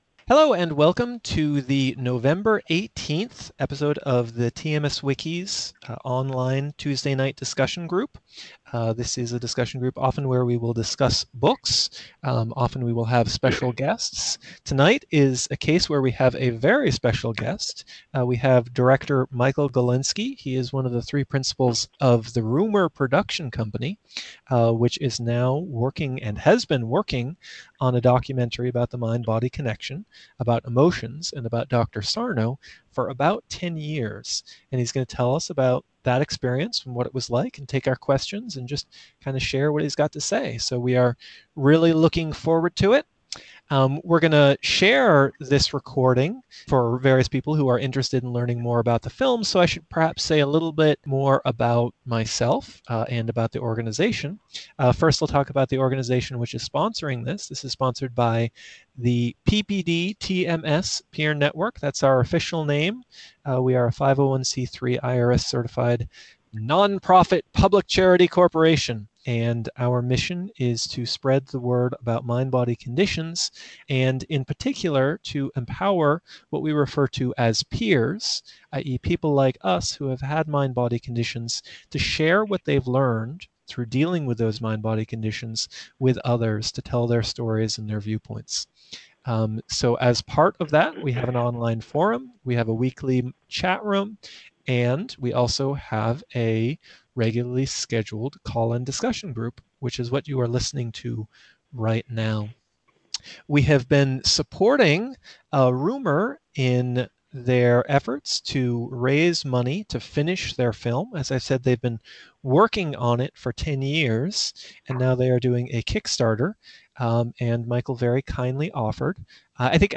Thanks to everyone who called in and participated as well. It was a great discussion!